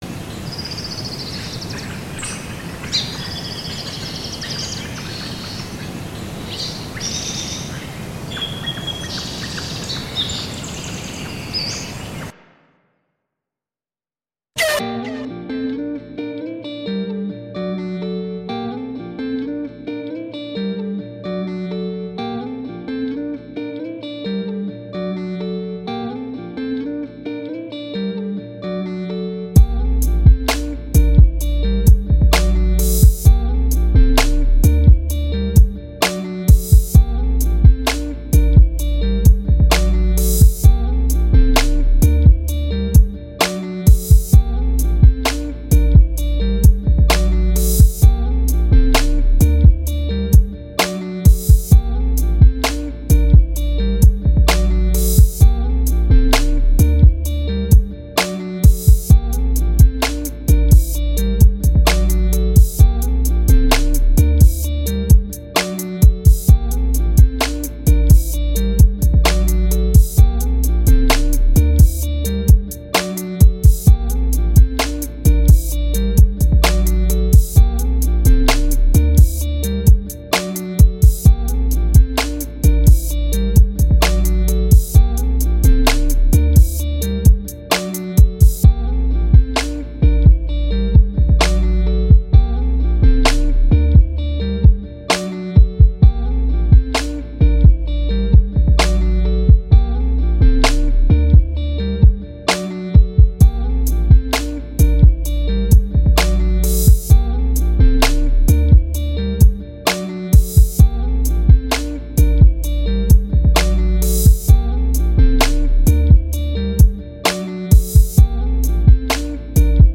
This beat absolutely SCREAMS 2006 album-filler instrumental. No real character, just there.
Music / Pop
randb instrumental old school 2000s music sample guitar strings hip hop hi hat snare kick bass